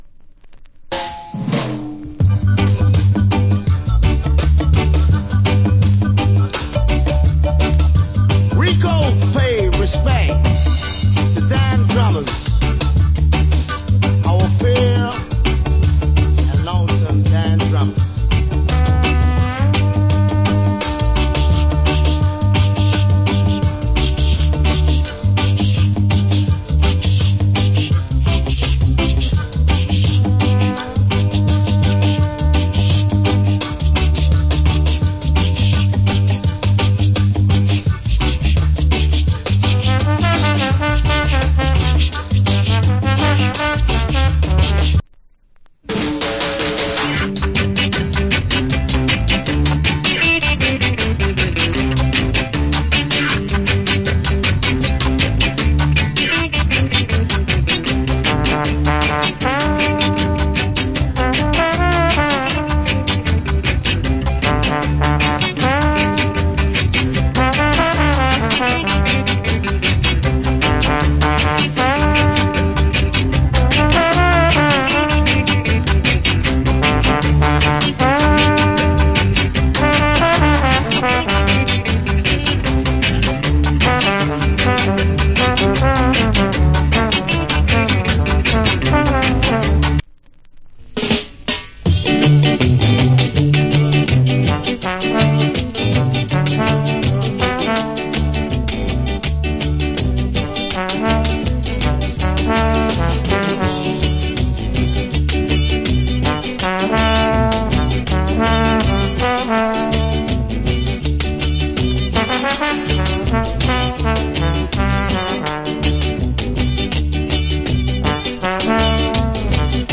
Nice Trombone Early Reggae Inst.